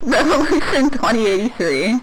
revolution2083_dog.ogg